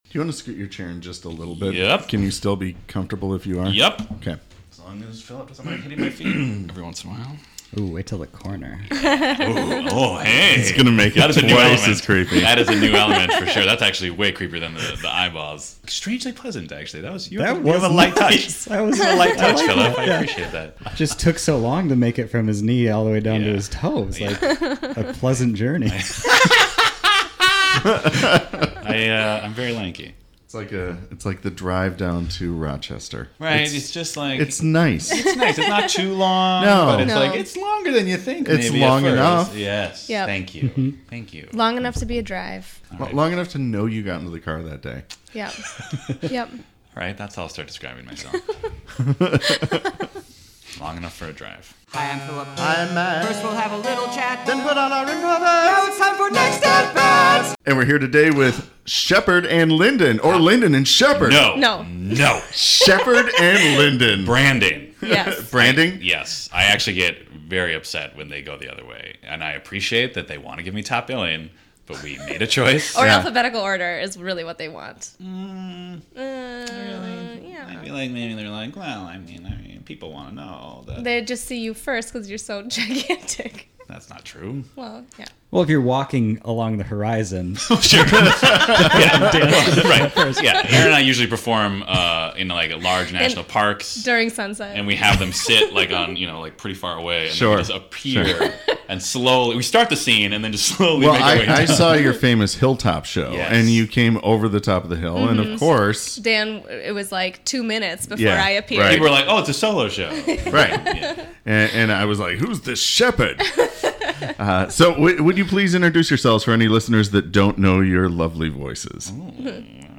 interview an improv team (or other artist) and then improvise with them